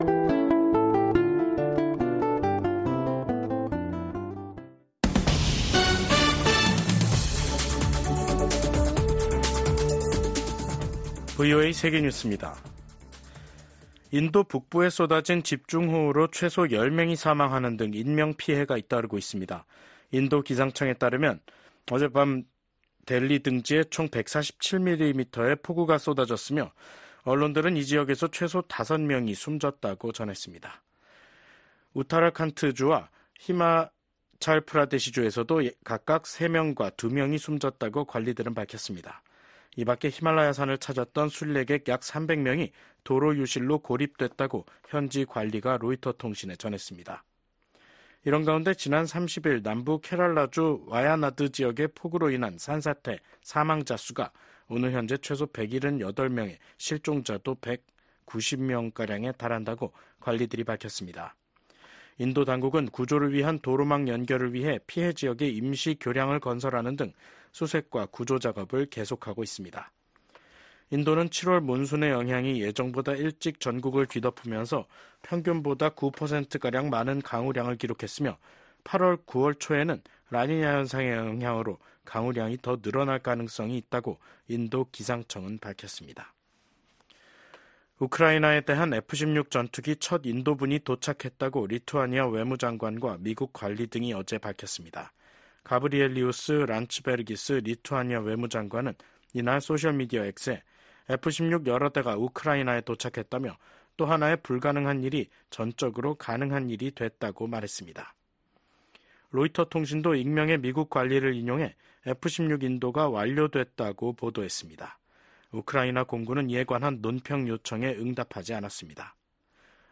VOA 한국어 간판 뉴스 프로그램 '뉴스 투데이', 2024년 8월 1일 3부 방송입니다. 올 하반기 미한 연합훈련인 을지프리덤실드(UFS)가 오는 19일부터 실시됩니다.